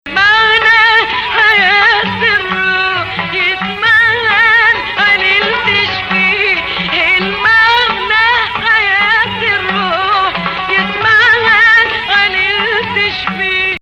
Bayati/Saba 5
again, ambiguous (=#04). Coloring it Saba this time